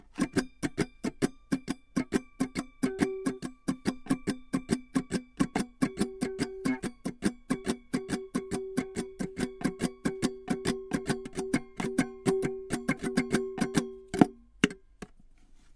描述：一包我用在办公室找到的旧玩具吉他得到的一些有趣的声音:)希望这对某人有用。 装备：玩具吉他，Behringer B1，便宜的支架，Presonus TubePRE，MAudio Audiophile delta 2496.
Tag: 卡通 字符串 玩具 玩具吉他 吉他